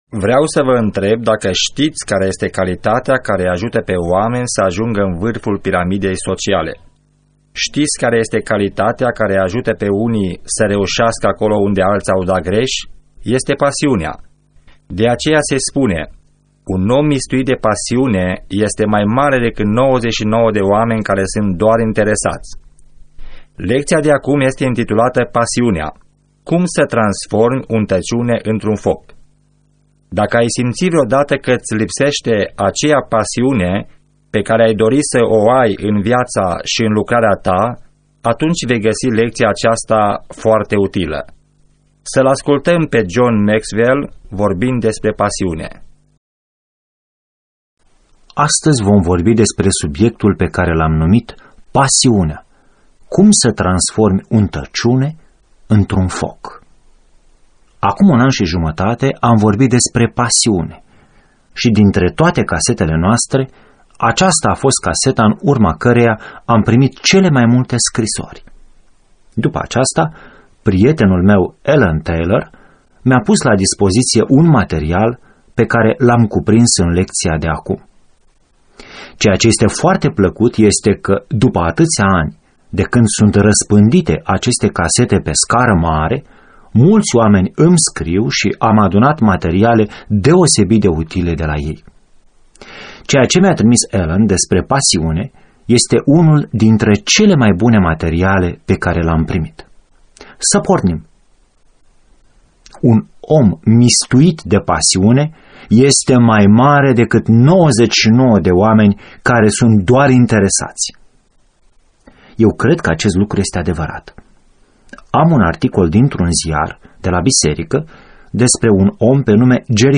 Săptămâna aceasta discutam cu o persoană, care are o poziție de conducere, despre mesajul cărților lui John Maxwell privitor la leadership. Mi-am amintit de o serie de casete pe care le am de ceva timp.